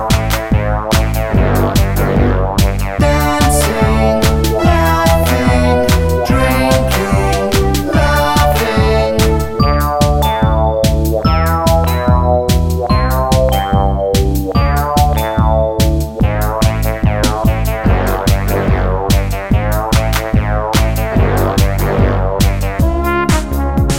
Medley Pop (1980s)